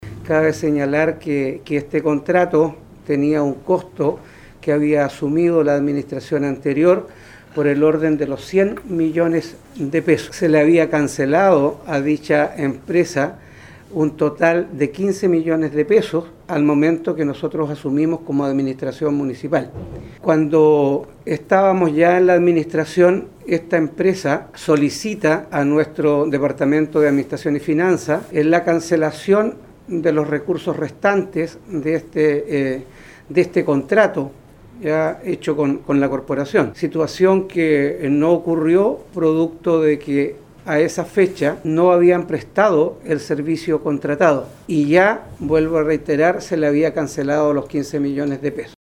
En una contrademanda realizada por la corporación municipal contra la empresa, se señalaba que nunca se habían efectuado esas capacitaciones, tal cual indicó el alcalde Carlos Gómez.